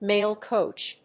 アクセントmáil còach